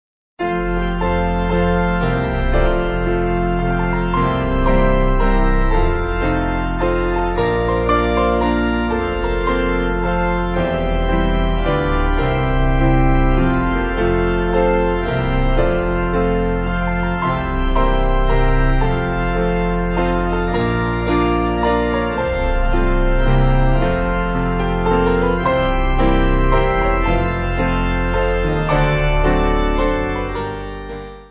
Organ/Piano Duet